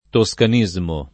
vai all'elenco alfabetico delle voci ingrandisci il carattere 100% rimpicciolisci il carattere stampa invia tramite posta elettronica codividi su Facebook toscanismo [ to S kan &@ mo ] (meno com. toscanesimo ) s. m.